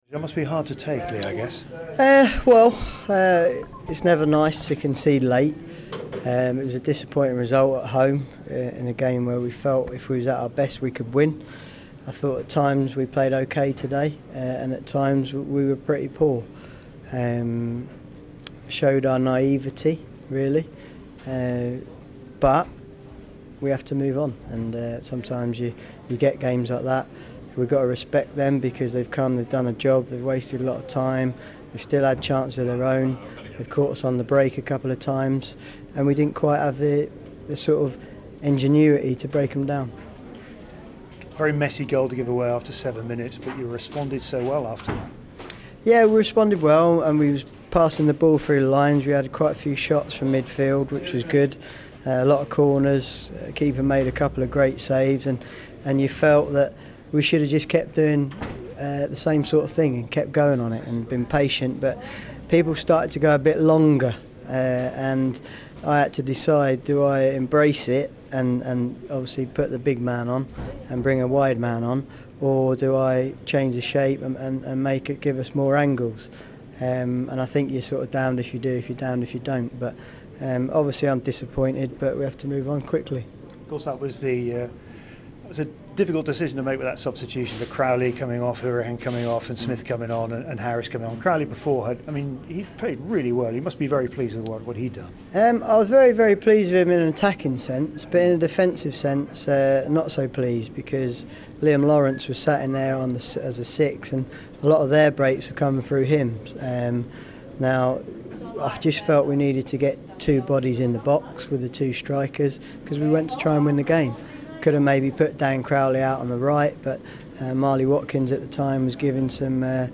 INTERVIEW: Barnsley head coach Lee Johnson post Shrewsbury